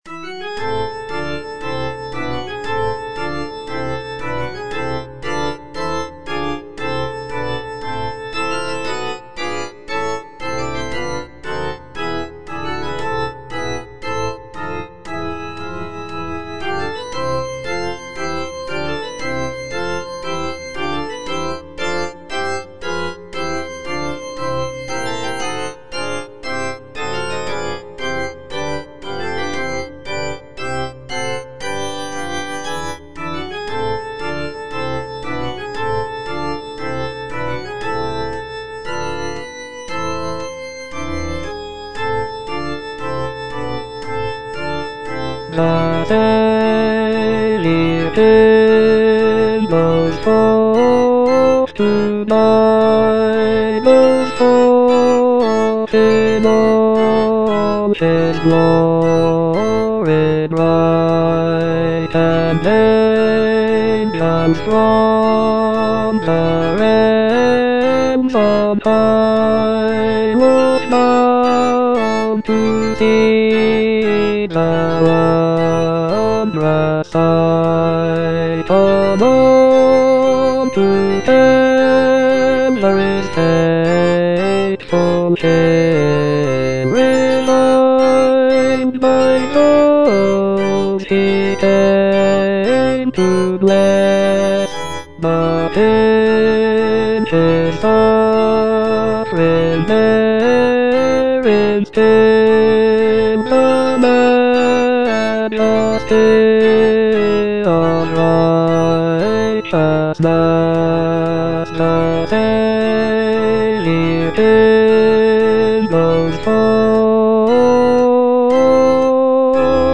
J.H. MAUNDER - OLIVET TO CALVARY 9. The march to Calvary - Bass (Voice with metronome) Ads stop: auto-stop
"Olivet to Calvary" is a sacred cantata composed by John Henry Maunder in 1904.